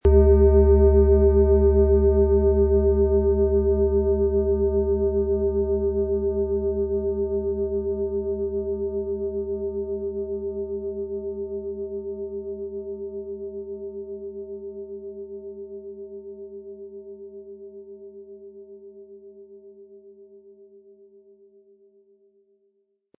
Planetenton 1 Planetenton 2
• Mittlerer Ton: Neptun
PlanetentöneMars & Neptun
HerstellungIn Handarbeit getrieben
MaterialBronze